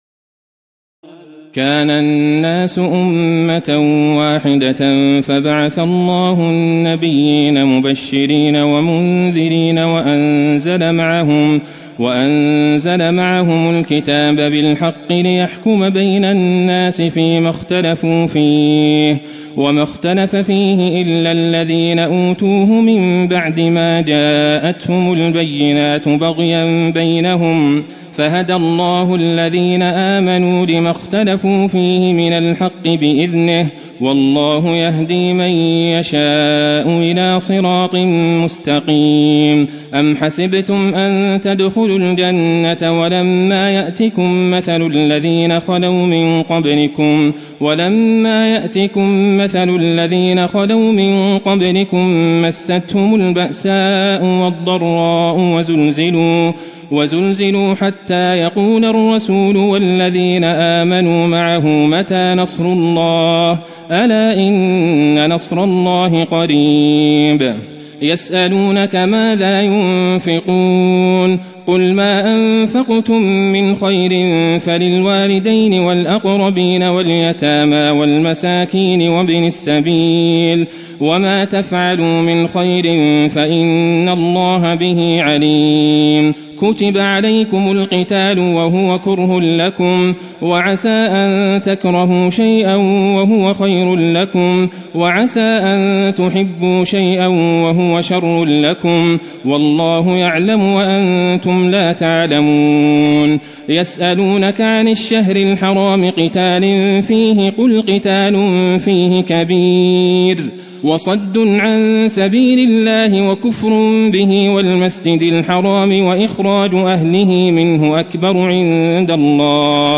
تراويح الليلة الثانية رمضان 1435هـ من سورة البقرة (213-263) Taraweeh 2 st night Ramadan 1435H from Surah Al-Baqara > تراويح الحرم النبوي عام 1435 🕌 > التراويح - تلاوات الحرمين